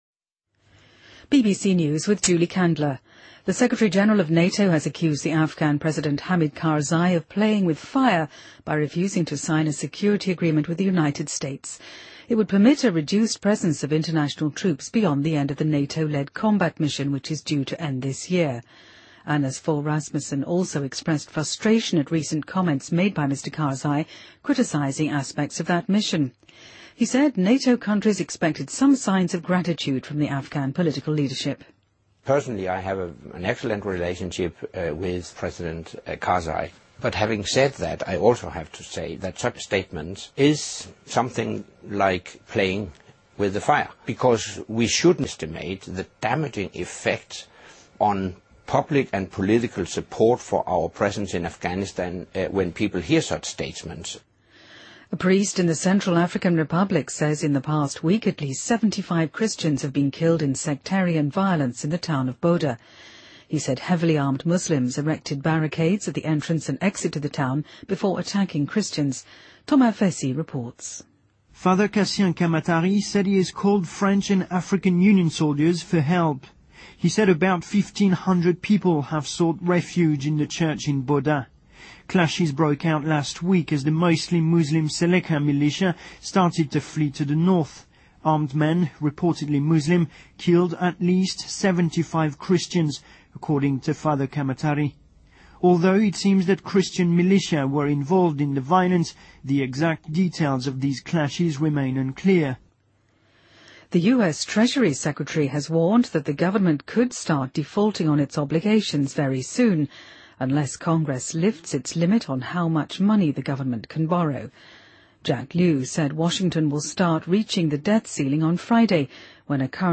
BBC news,2014-02-04